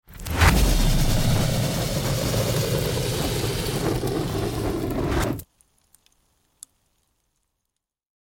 جلوه های صوتی
دانلود صدای آتش 4 از ساعد نیوز با لینک مستقیم و کیفیت بالا